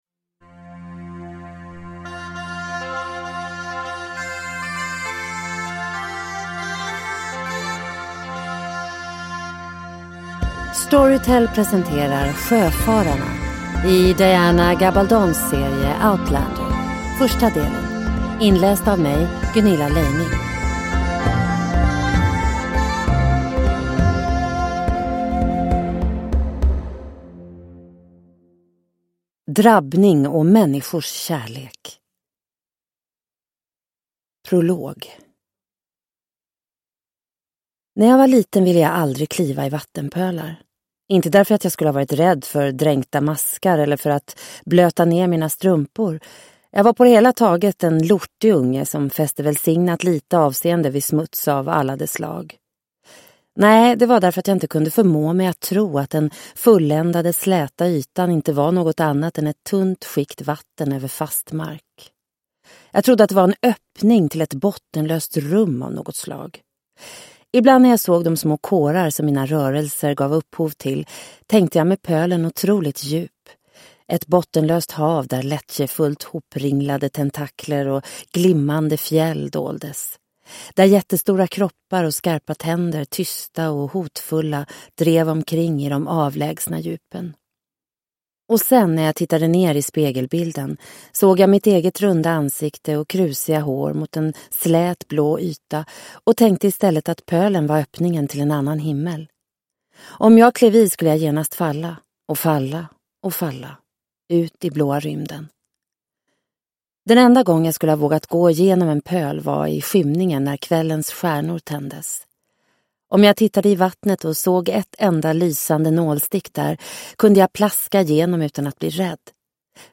Sjöfararna - del 1 – Ljudbok – Laddas ner